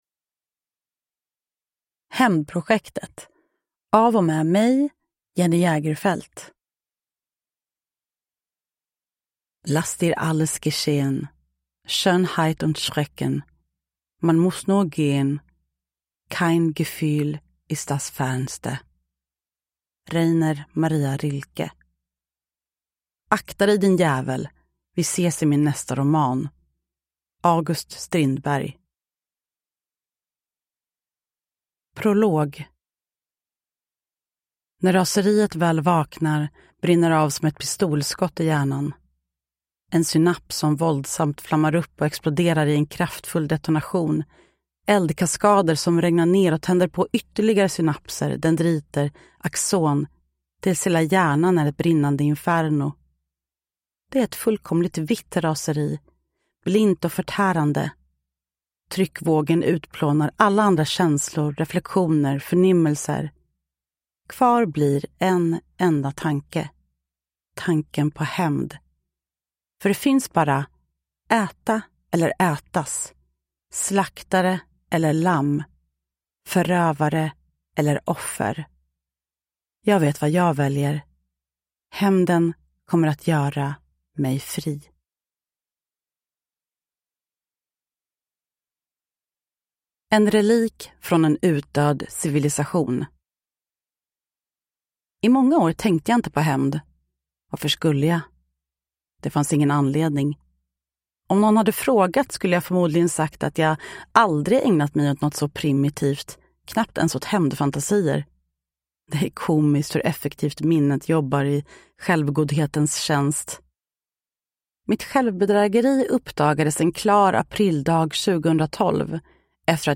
Hämndprojektet – Ljudbok
Uppläsare: Jenny Jägerfeld